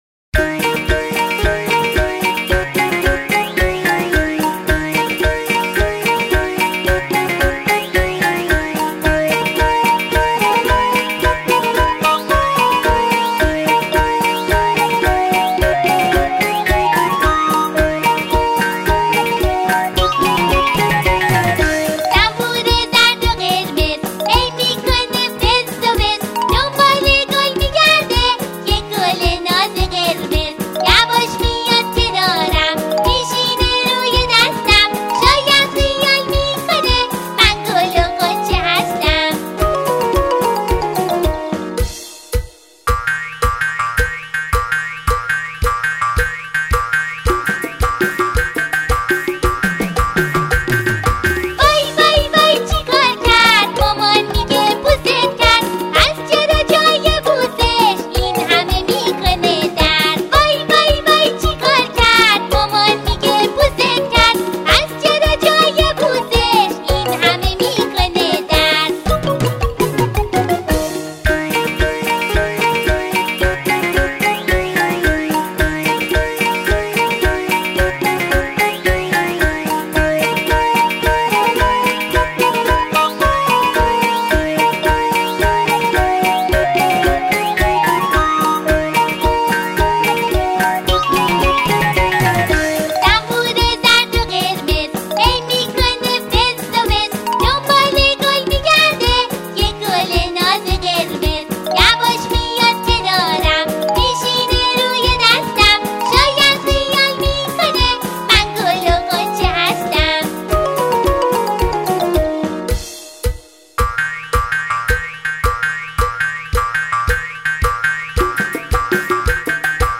همخوان زن
آنها در این قطعه، شعری کودکانه را اجرا می‌کنند.